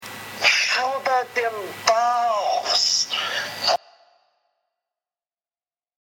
Tags: hoodrat profanity funny yelling